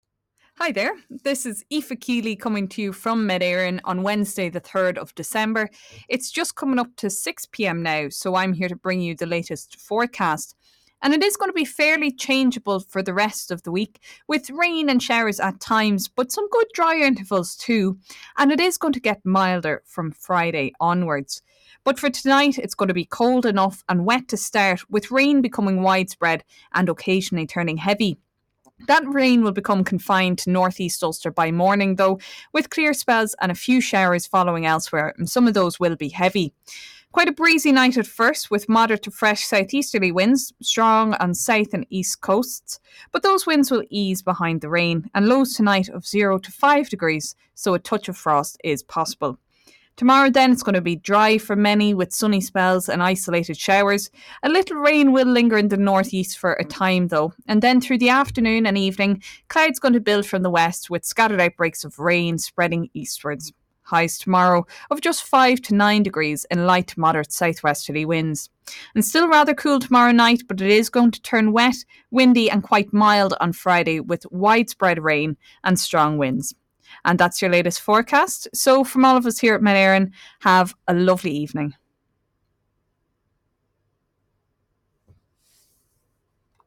The latest weather forecast from Met Éireann, the Irish Meteorological Service. A new forecast will be issued 3 times a day - at approximately 6am, 1pm and 6pm - with a detailed weather forecast for today and tonight, the outlook for the week ahead and details on any weather warnings issued.